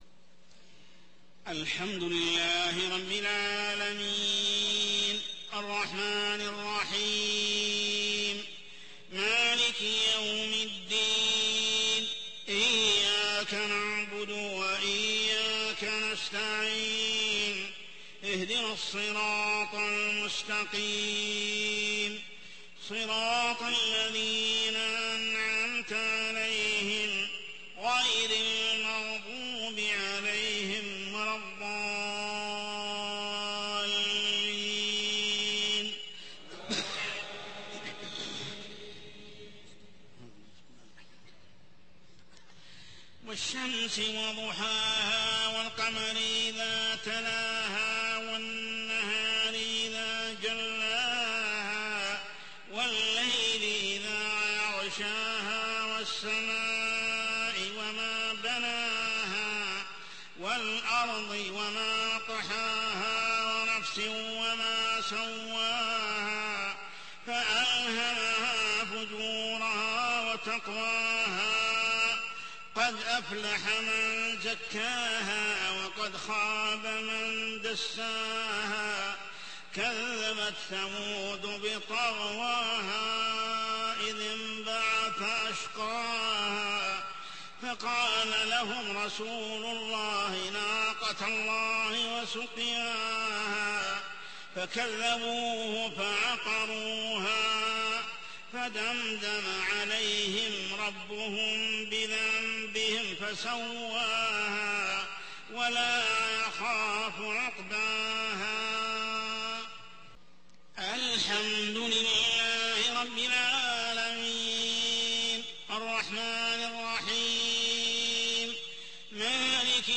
صلاة العشاء عام 1428هـ سورتي الشمس و الزلزلة كاملة | Isha prayer Surah Al-shamis and Az-Zalzalah > 1428 🕋 > الفروض - تلاوات الحرمين